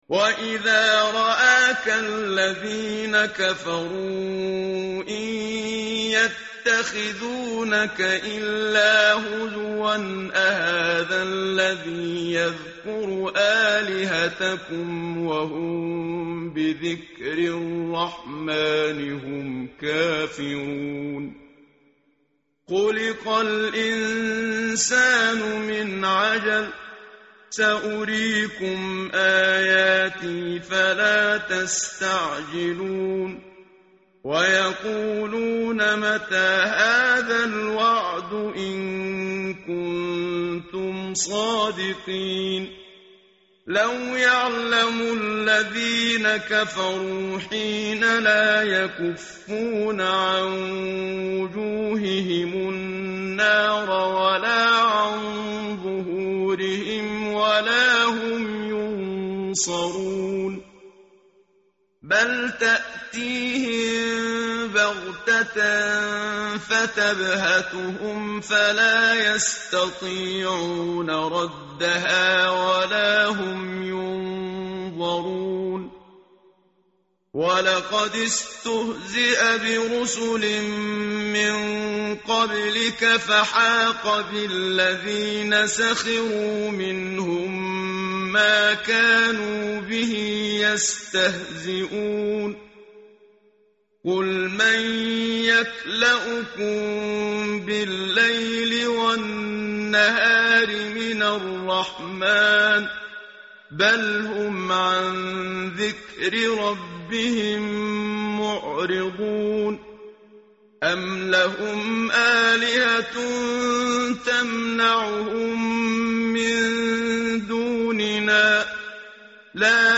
tartil_menshavi_page_325.mp3